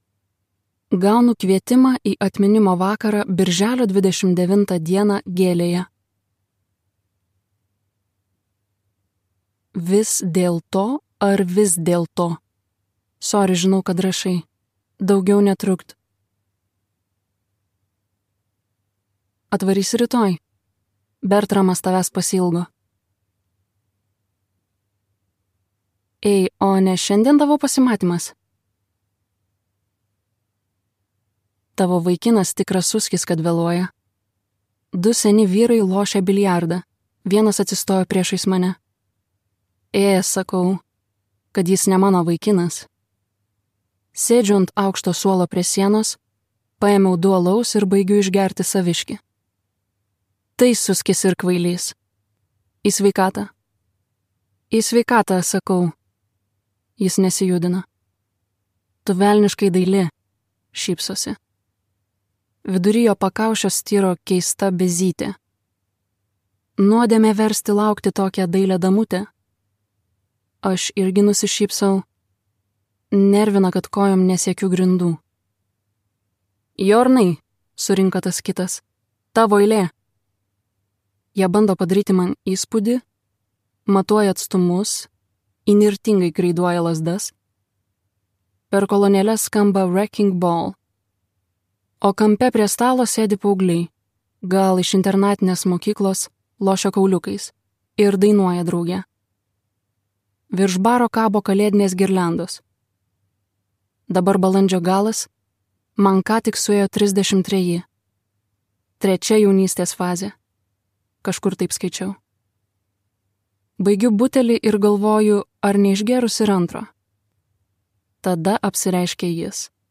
Audio Tour de chambre